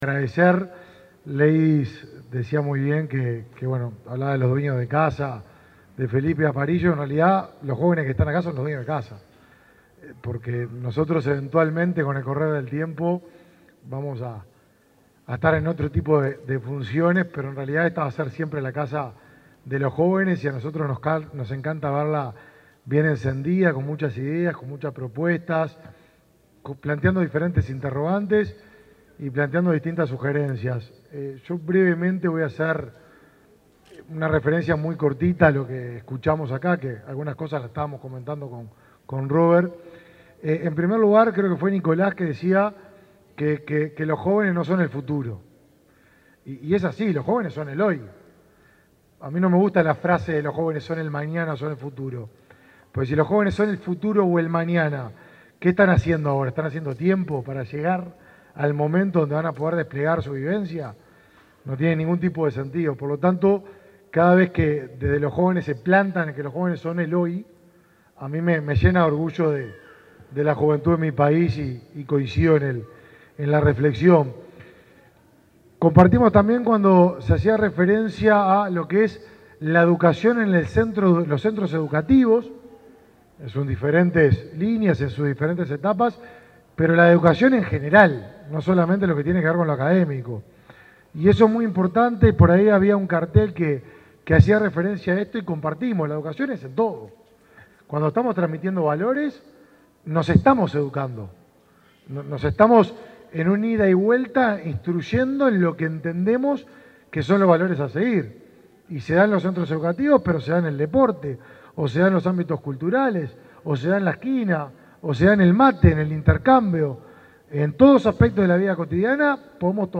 Palabras del ministro de Desarrollo Social, Martín Lema